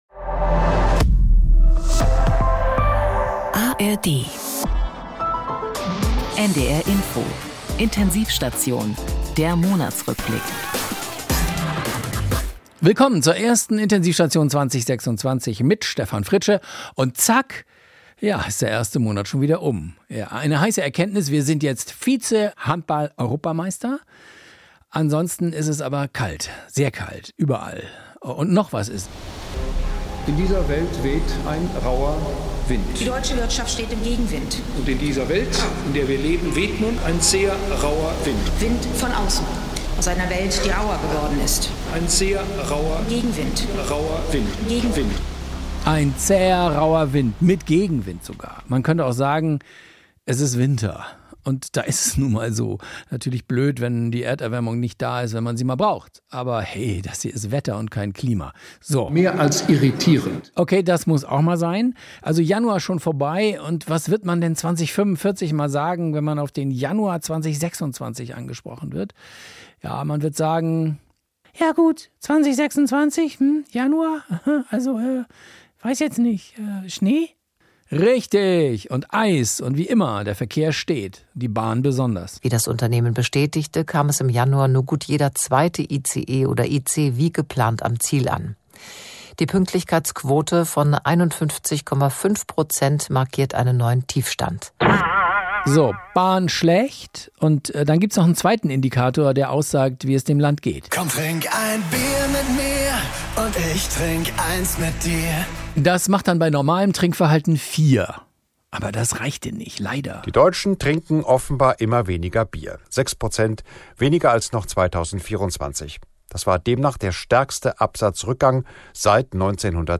Intensiv-Station - Satire von NDR Info Faule Haut und andere Beschwerden Play episode February 2 50 mins Bookmarks Episode Description Verflucht kalt, der Januar.